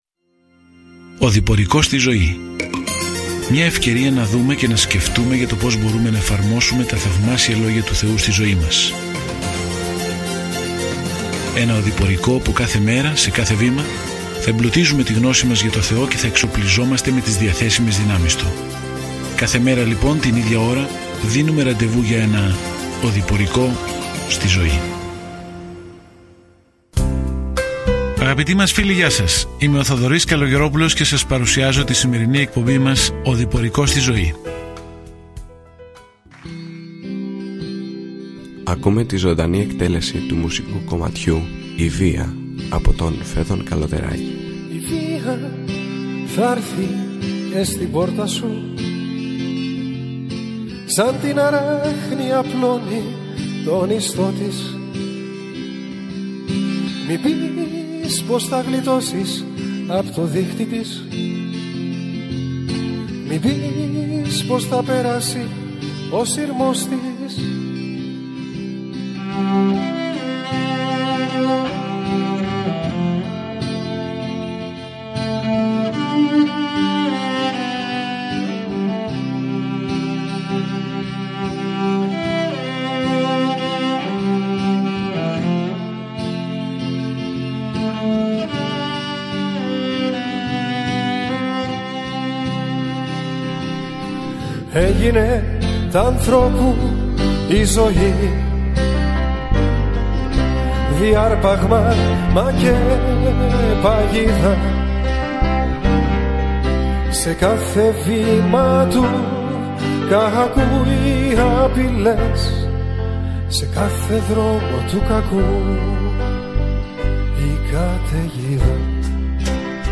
Κείμενο ΙΩΒ 3:1-2-12 Ημέρα 3 Έναρξη αυτού του σχεδίου Ημέρα 5 Σχετικά με αυτό το σχέδιο Σε αυτό το δράμα του ουρανού και της γης, συναντάμε τον Ιώβ, έναν καλό άνθρωπο, στον οποίο ο Θεός επέτρεψε στον Σατανά να επιτεθεί. όλοι έχουν τόσες πολλές ερωτήσεις σχετικά με το γιατί συμβαίνουν άσχημα πράγματα. Καθημερινά ταξιδεύετε στον Ιώβ καθώς ακούτε την ηχητική μελέτη και διαβάζετε επιλεγμένους στίχους από τον λόγο του Θεού.